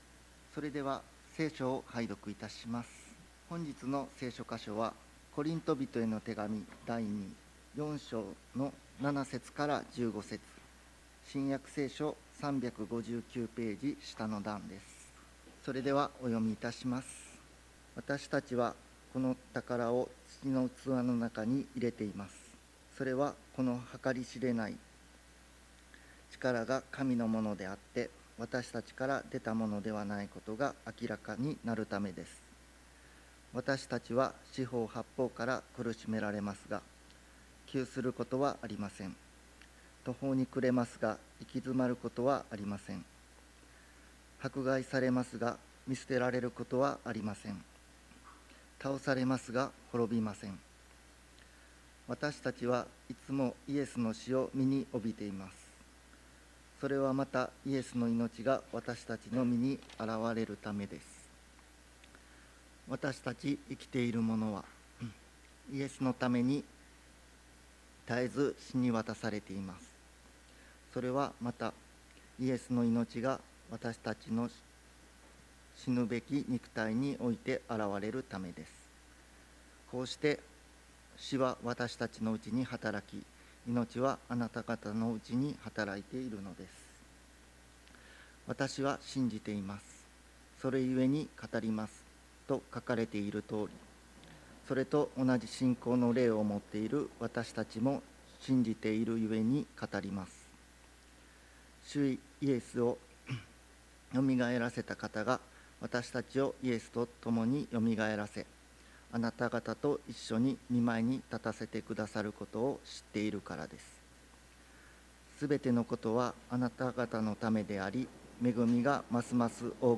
礼拝メッセージ「神の御力」│日本イエス・キリスト教団 柏 原 教 会